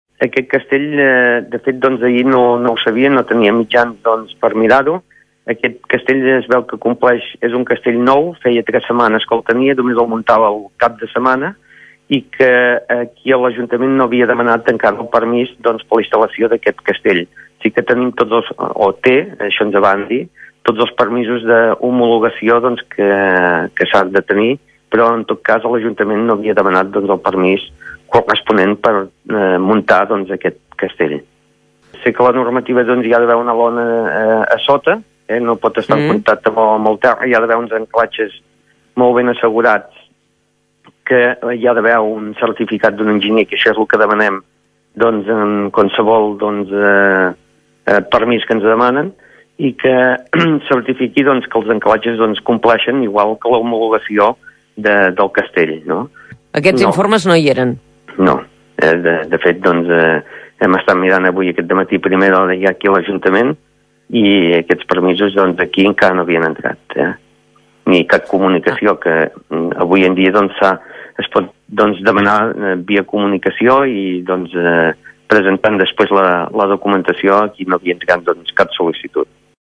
En declaracions a El Matí de Catalunya Ràdio, Balliu ha assegurat que el castell estava homologat per l’empresa i tenia tots els papers en regla, però no comptava amb el preceptiu permís de l’ajuntament ni s’havia comunicat la seva instal·lació.